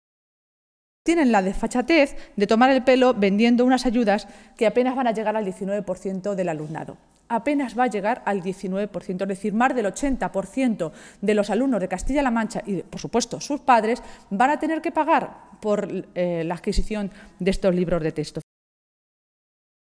Maestres e pronunciaba de esta manera esta mañana, en una comparecencia ante los medios de comunicación, en Toledo, en la que se refería al hecho de que Castilla-La Mancha afronta el arranque del nuevo curso escolar como la única región que va a aplicar, íntegramente y desde el principio, esa misma Ley Wert.